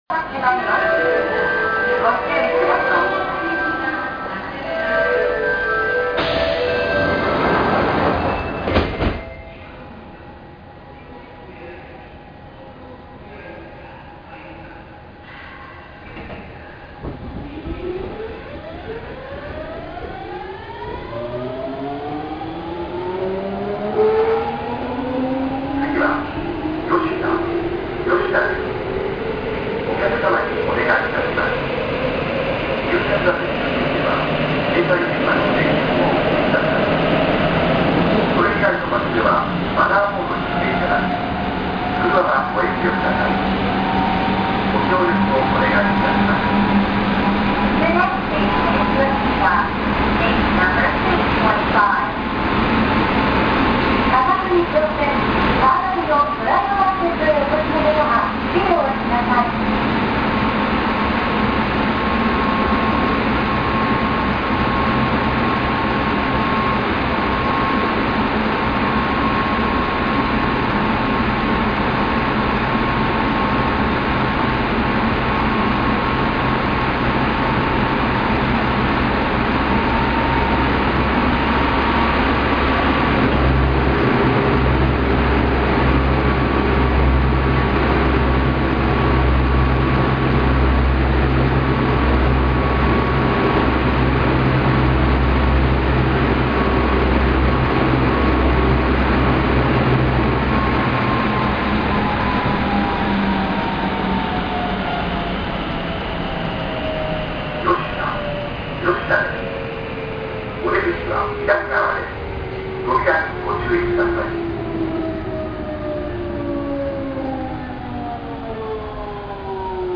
・7000系日立GTO走行音
【けいはんな線】荒本→吉田（2分17秒：1.04MB）
一方こちらが日立の車両のモーター音。三菱と比べれば転調の激しさはまだ落ち着いているのではないでしょうか。